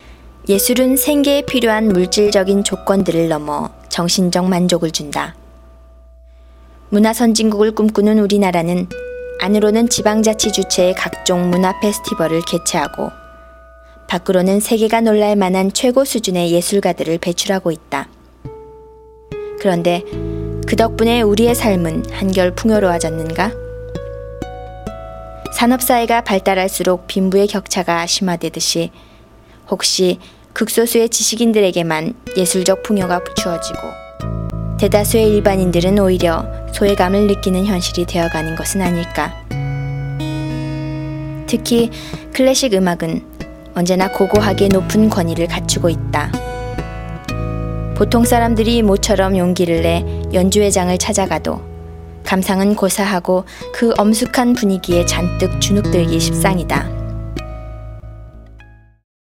韓国語ナレーター・韓国語ナレーション